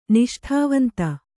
♪ niṣṭhāvanta